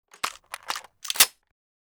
musket_reload.wav